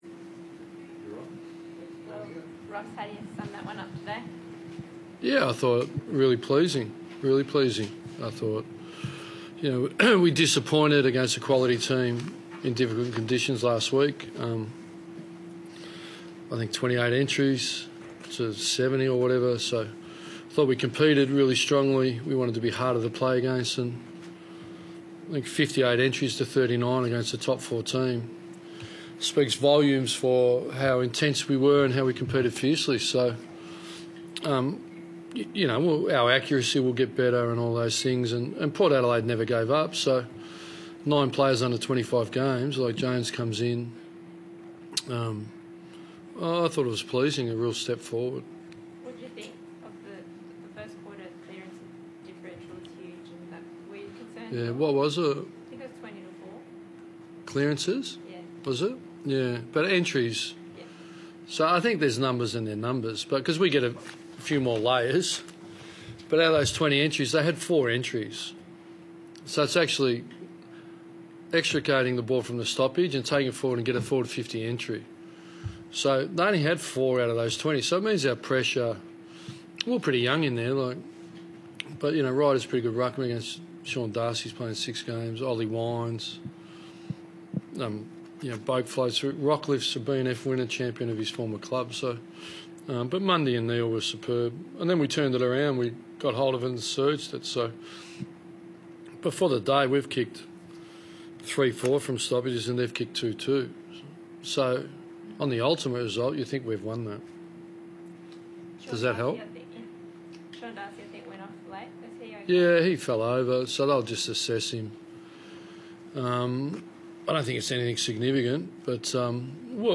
Ross Lyon post-match Q&A - Round 17 v Port Adelaide
Ross Lyon spoke to media after the round 17 win over Port Adelaide